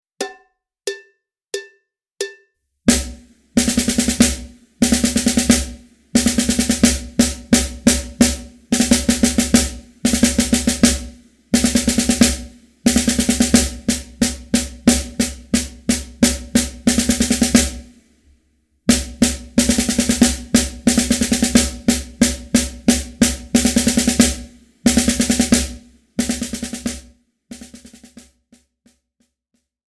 Besetzung: Schlagzeug
06 - 7-Stroke-Roll
06_-_7-Stroke-Roll.mp3